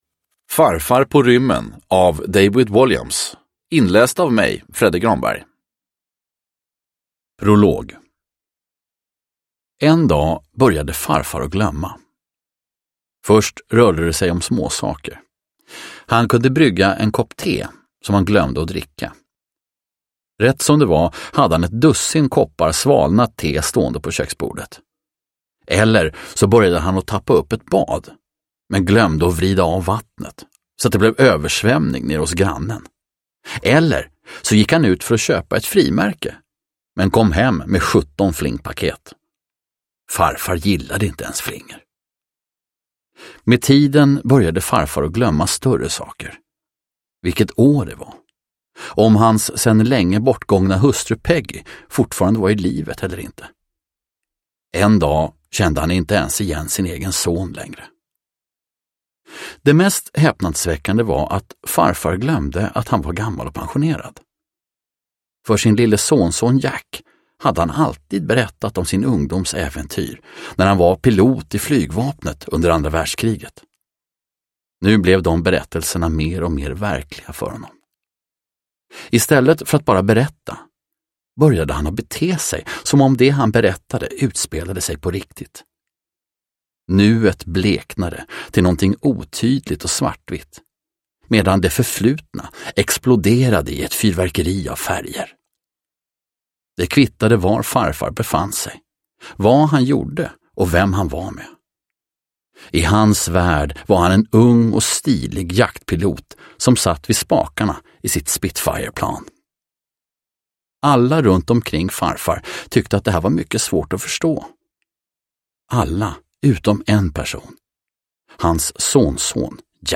Farfar på rymmen – Ljudbok – Laddas ner